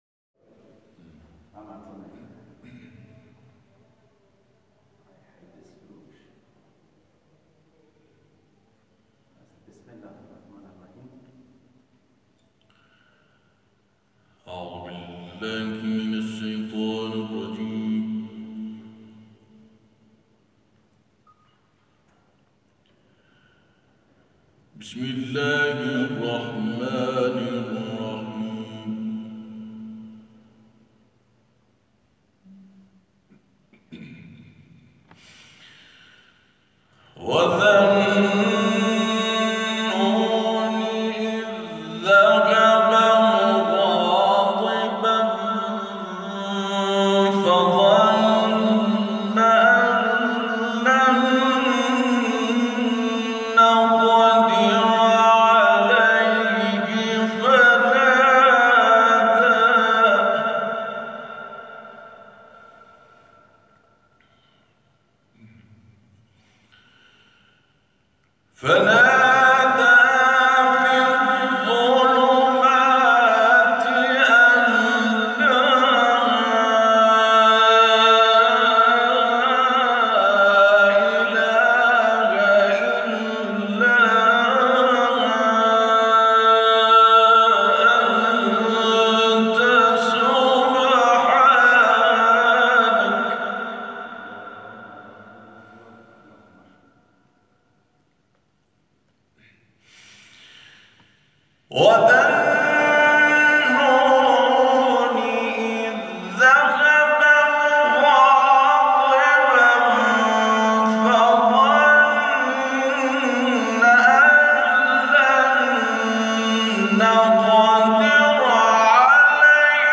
تلاوت
در دومین محفل انس با قرآن
آیات 87 تا 91 سوره «انبیاء» را با صوتی دلنشین تلاوت کرده است